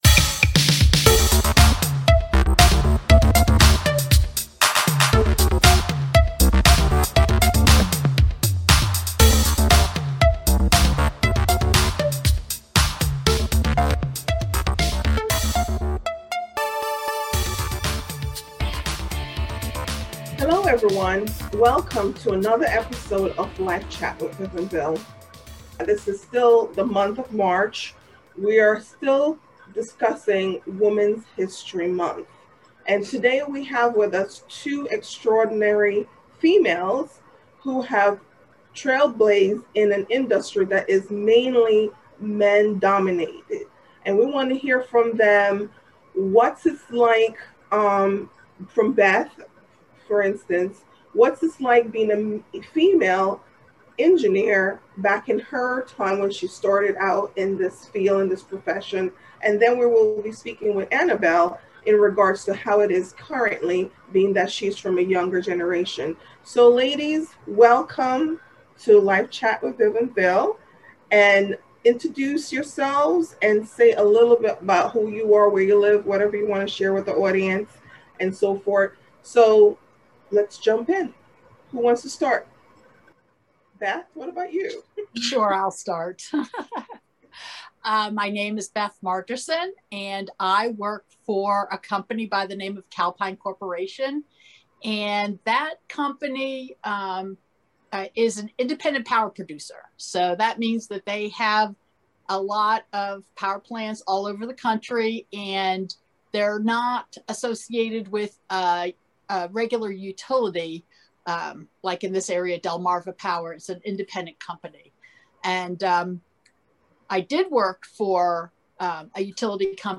In today’s video, we have two extraordinary women engineers. They explain what it’s like being a women in their field of work, and the obstacles that they’ve faced along the way!